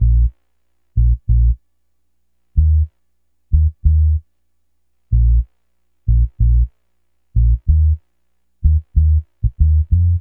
02N-BAS-94-R.wav